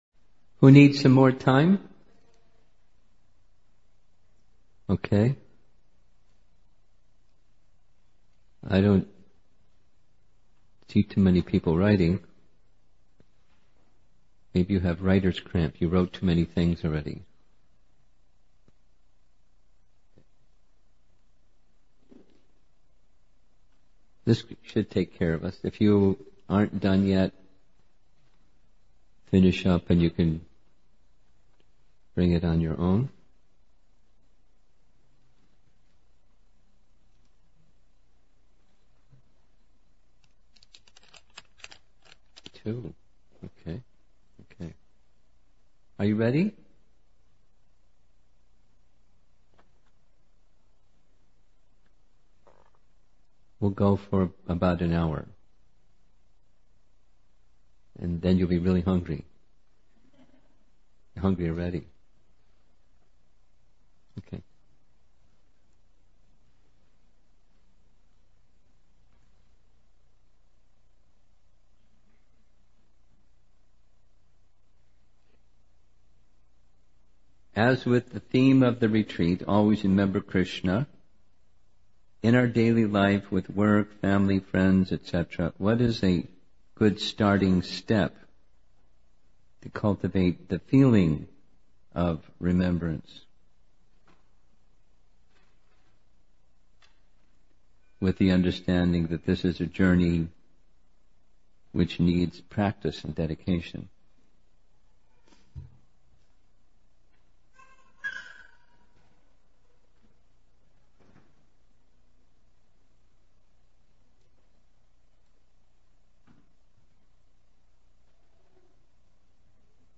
B11–Questions and Answers – New Year Retreat Chicago December 2017